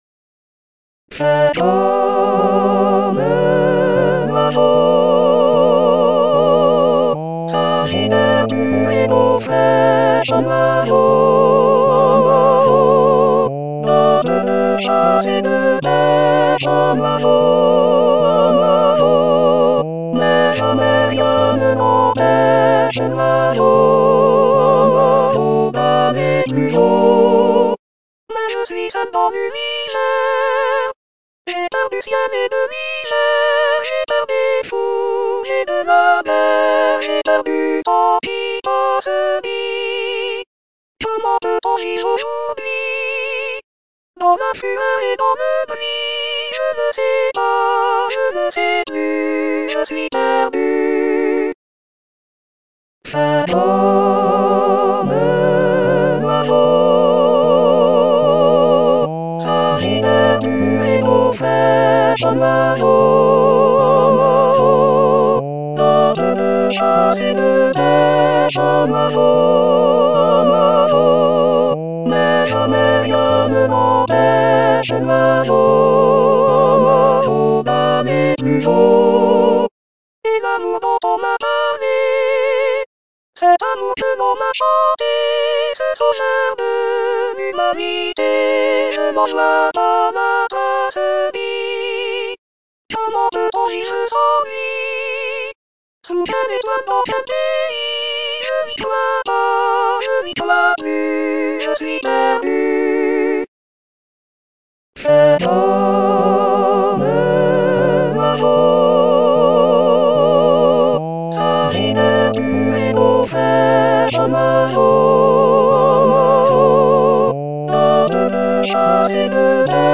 L'harmonie est celle des comédies musicales. Le résultat est très proche de l'original, pas toujours facile !